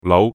Yue-lau5.mp3